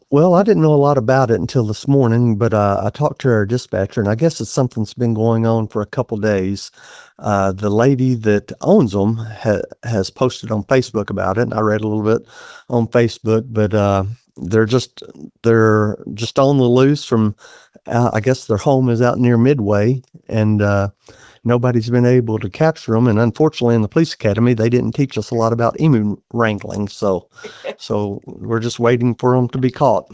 Mountain Home Police Chief